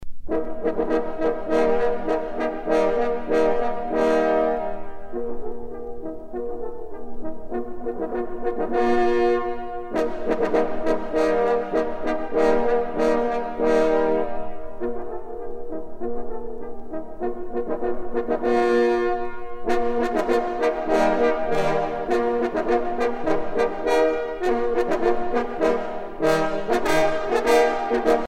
trompe - Fanfares et fantaisies de concert
circonstance : vénerie